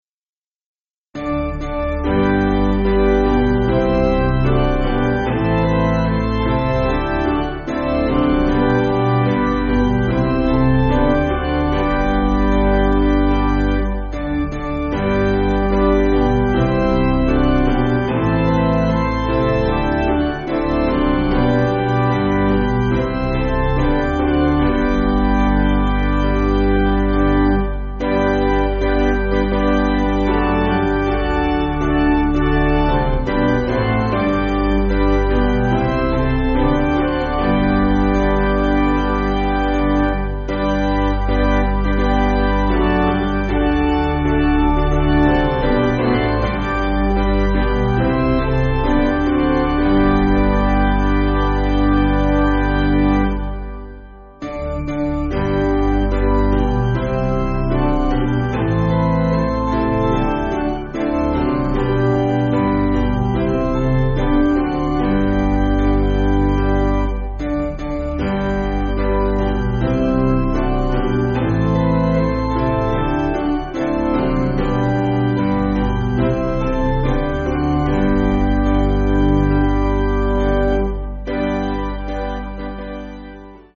Basic Piano & Organ
(CM)   3/G